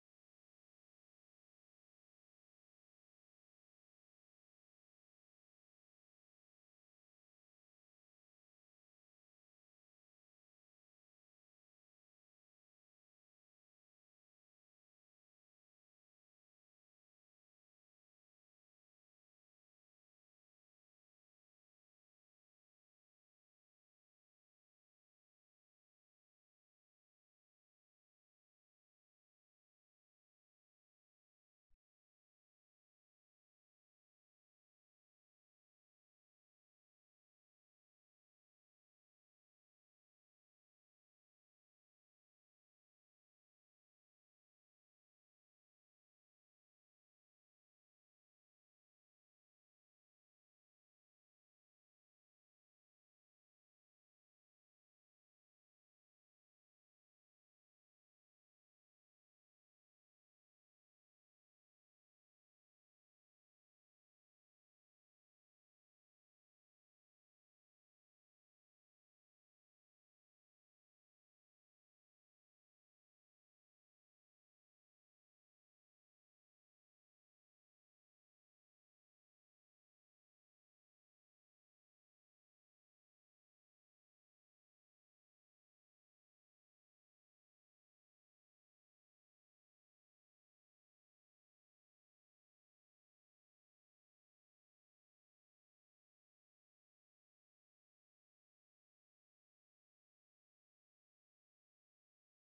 Note ronde
rondes enfantines
Répertoire de chansons populaires et traditionnelles
Pièce musicale inédite